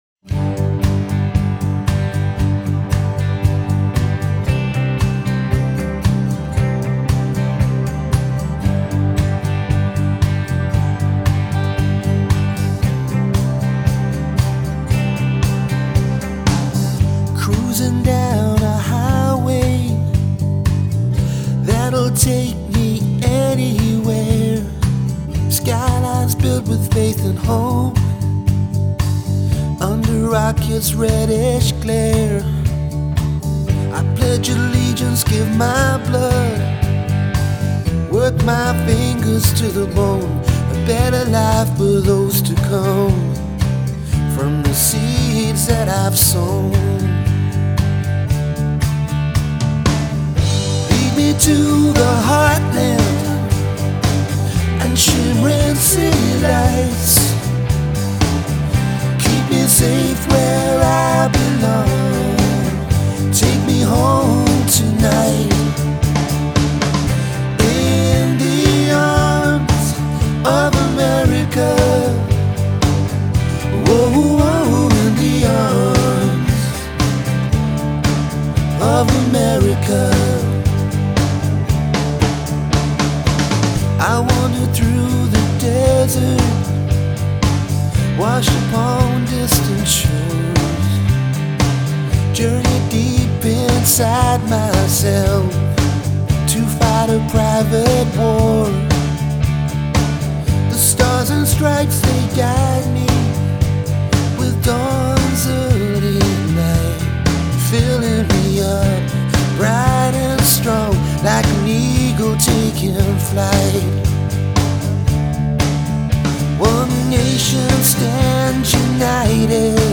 The song is riding the border of pop and country.
The instrumentals are up beat. The vocals are soft.
• Very good introduction, very upbeat and nice and smooth.